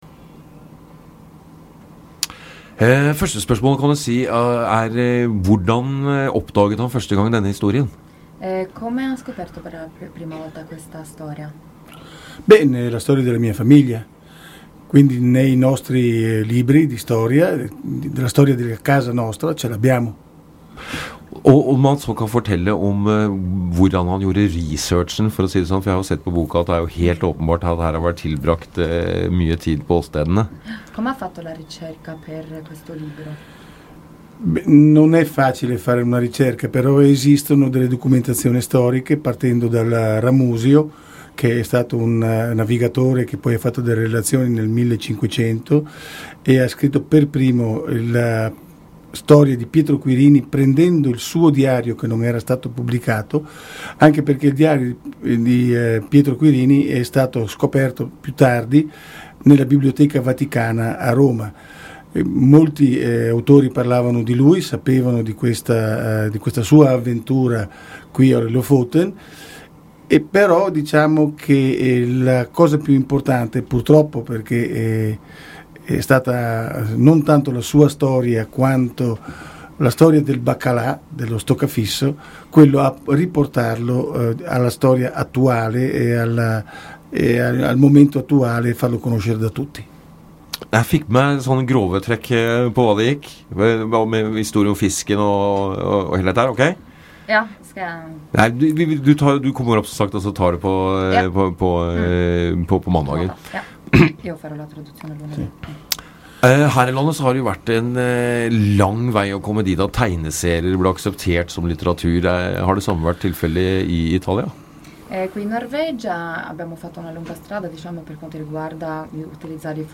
Intervista Radio Querini